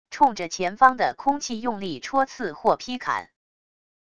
冲着前方的空气用力戳刺或劈砍wav音频